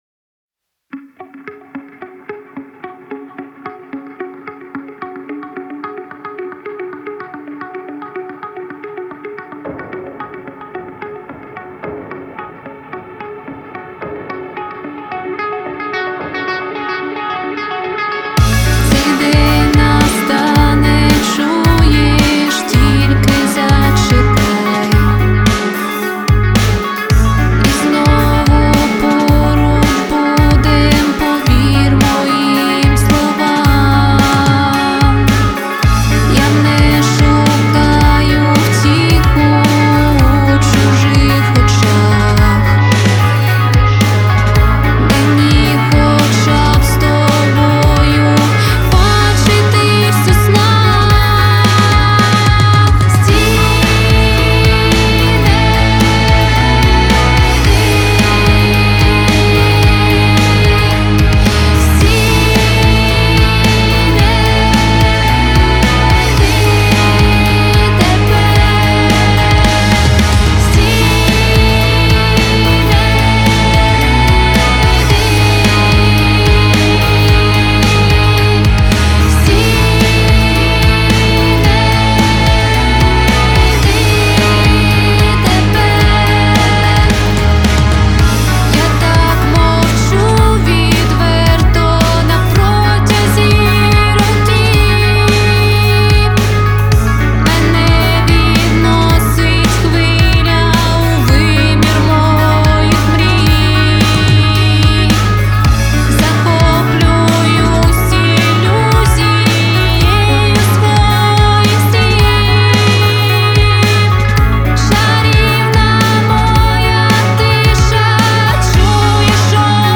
• Жанр: Rock, Indie